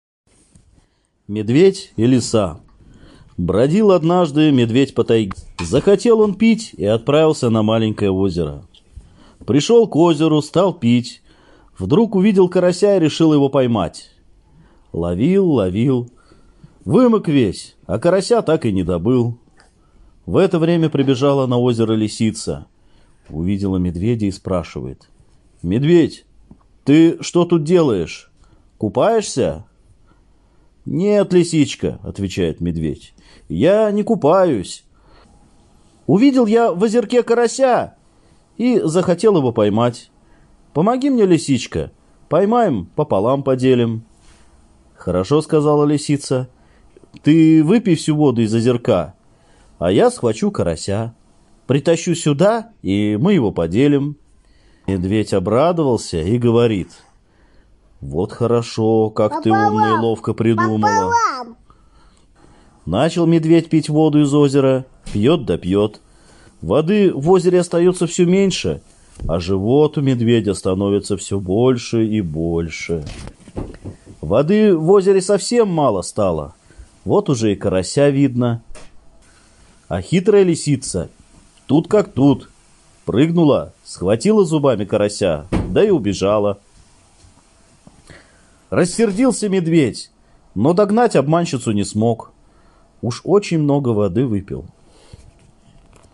Медведь и лиса - нанайская аудиосказка - слушать онлайн